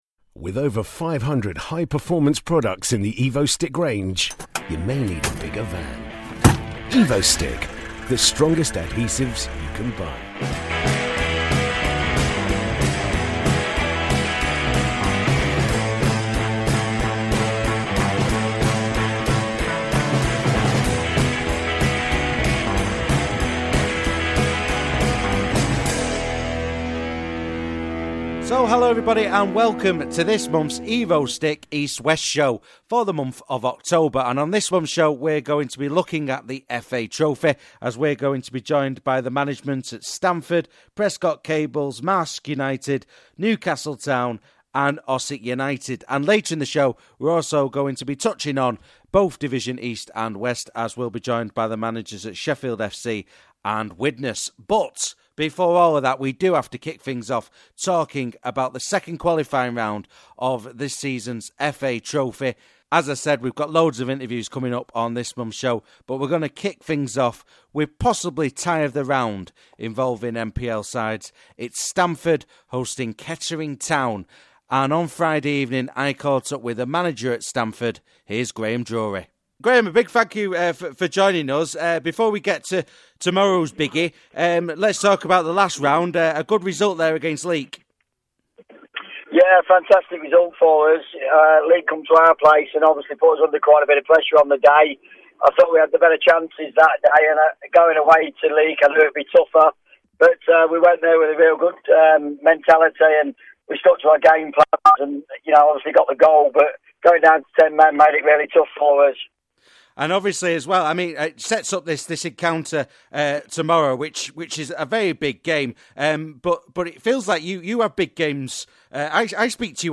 On this month's show we're joined by the Management at Prescot Cables, Stamford, Marske United, Newcastle Town, and Ossett United.